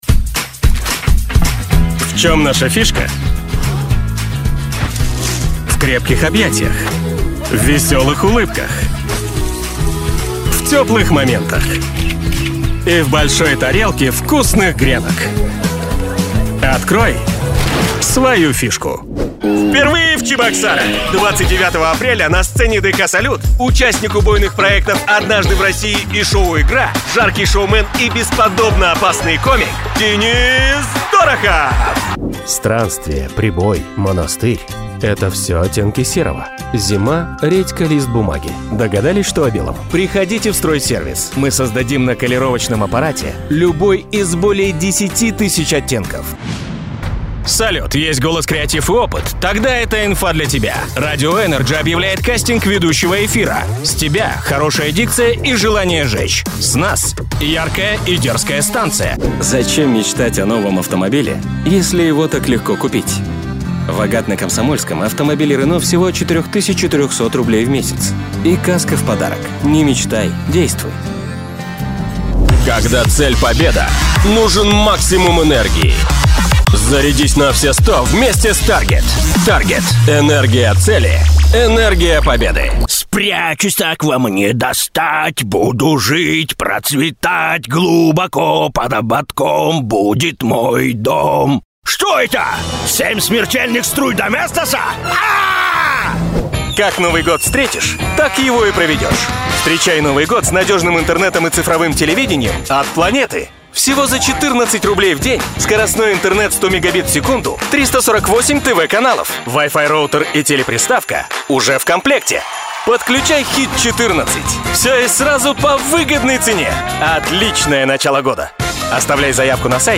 Тракт: Shure sm7b,AT-4040 Long Voice Master, RME BabyfacePRO-FS